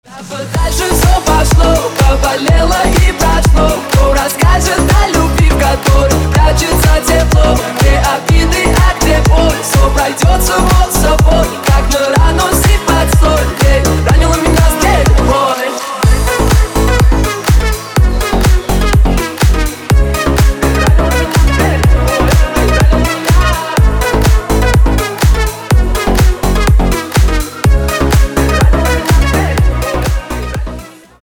• Качество: 320, Stereo
мужской вокал
Club House
электронная музыка